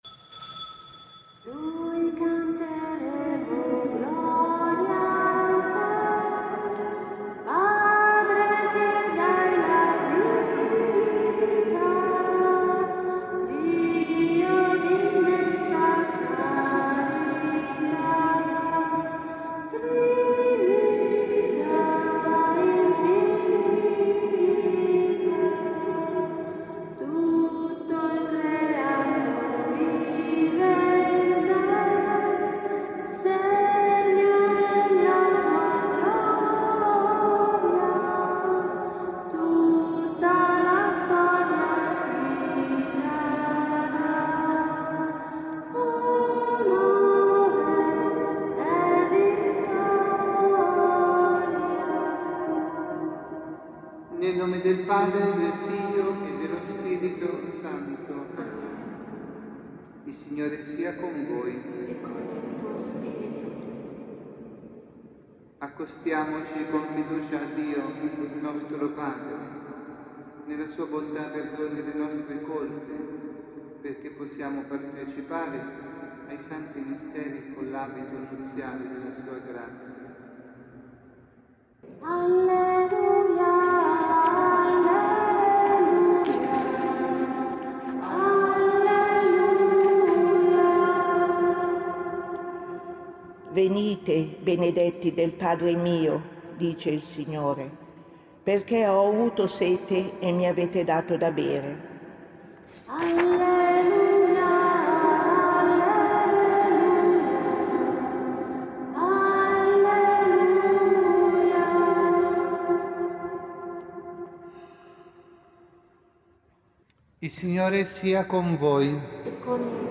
Omelia
dalla Parrocchia Santa Rita – Milano